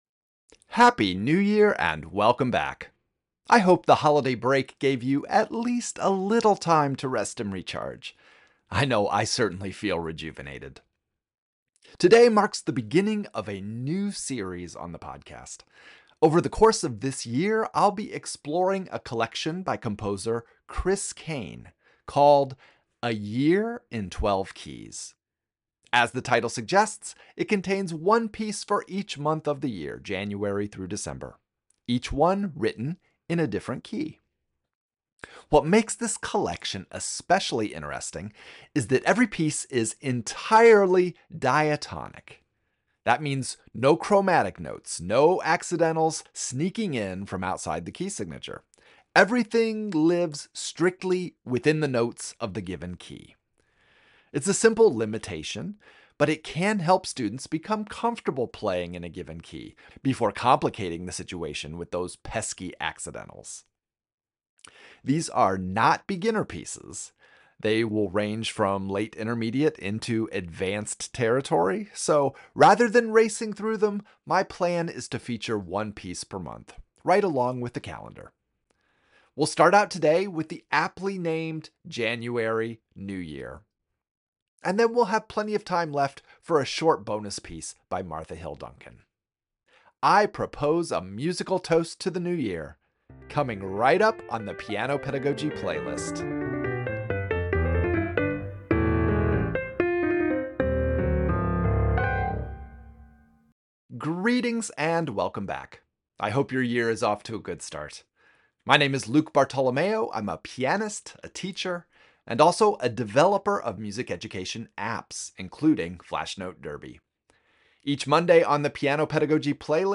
The first selection, "January - New Year" is a musical theatre ballad-without-words. The opening melody seems to speak the words “New Year,” setting a tone that’s calm, thoughtful, and full of possibility.